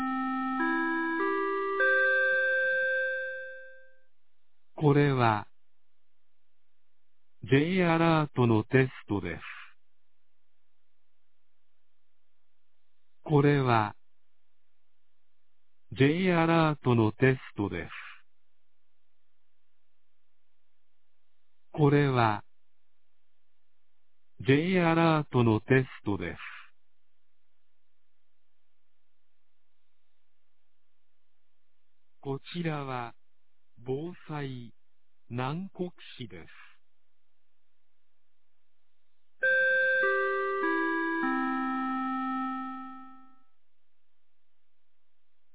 2024年05月22日 11時01分に、南国市より放送がありました。